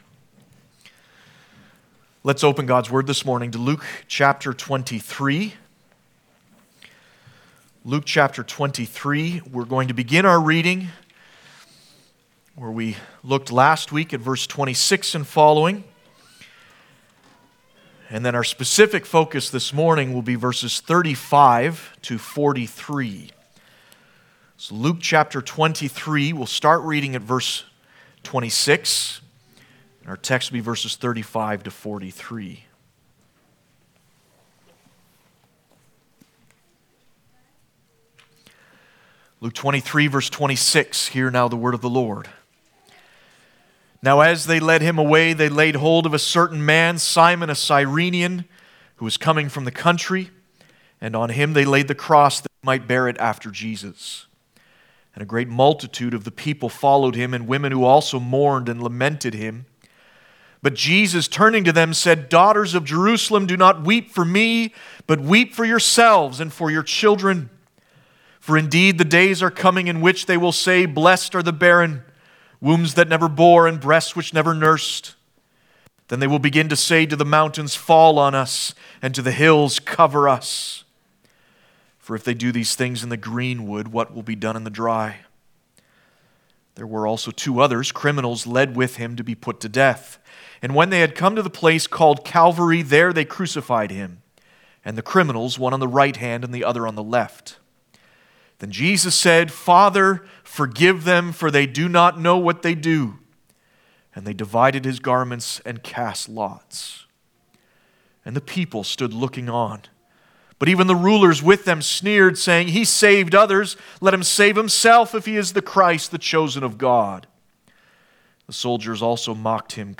Easter Passage: Luke 23:35-43 Service Type: Sunday Morning Topics